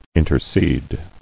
[in·ter·cede]